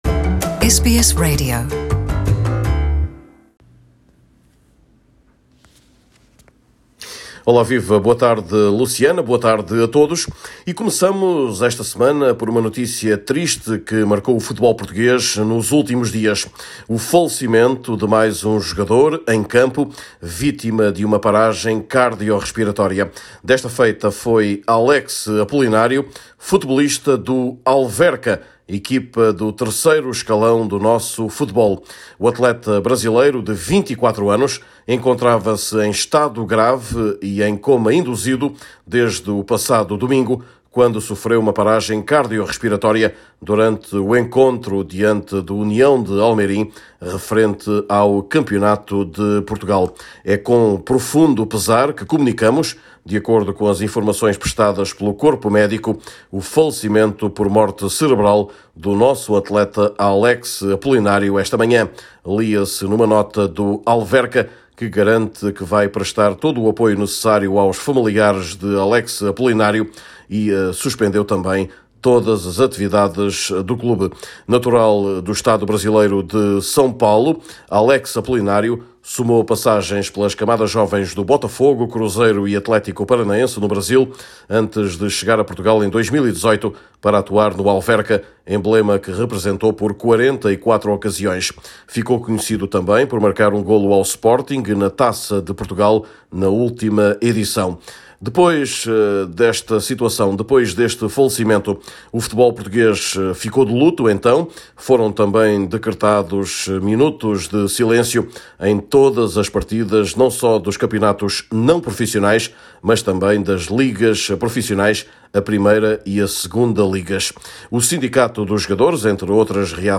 Neste boletim semanal, olhamos também para os jogadores mais valiosos do momento, com dois lusos nos sete primeiros lugares da tabela elaborada pelo Observatório do Futebol.